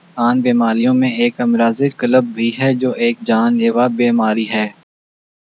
Spoofed_TTS/Speaker_02/11.wav · CSALT/deepfake_detection_dataset_urdu at main